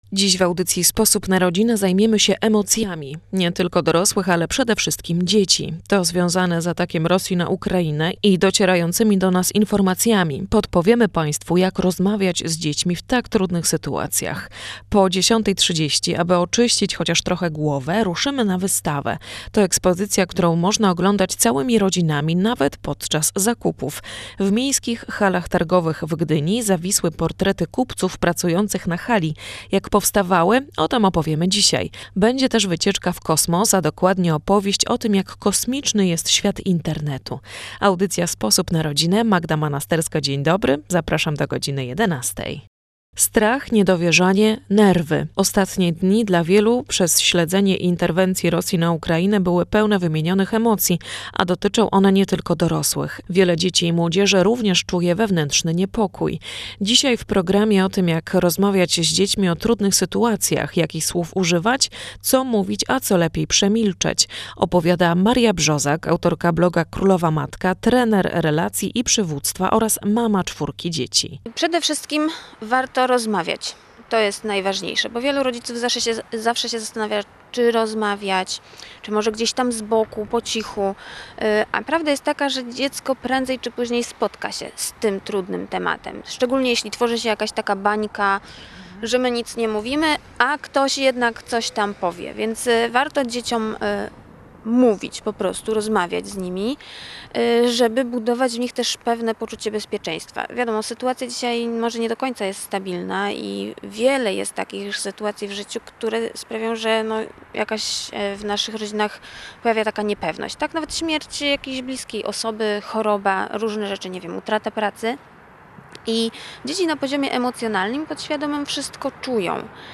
W niedzielnej audycji "Sposób na rodzinę" rozmawialiśmy głownie o emocjach.